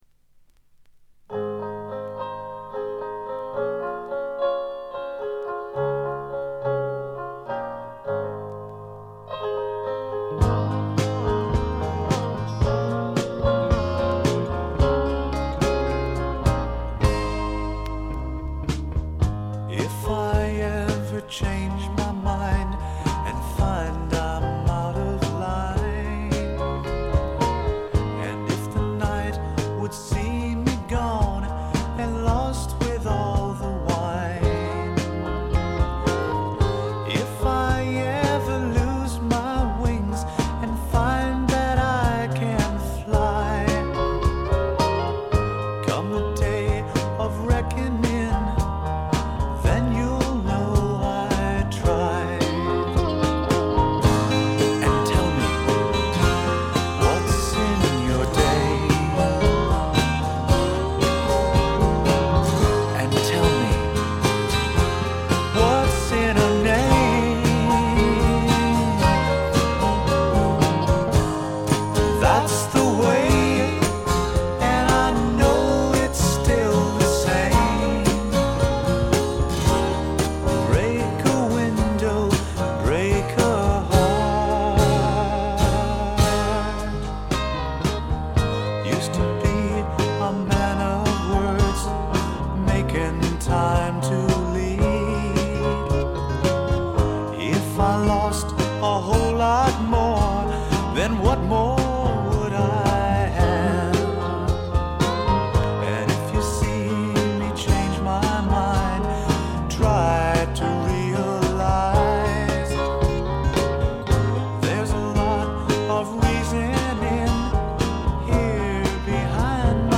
英国フォークロックの基本。
試聴曲は現品からの取り込み音源です。